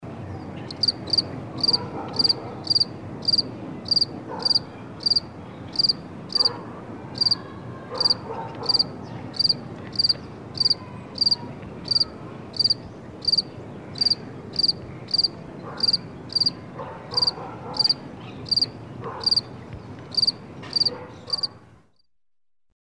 Стрекотание сверчков ночью и вечером на фоне природы в mp3
8. Звук сверчка в деревне
zvuki-derevni-i-sverchka.mp3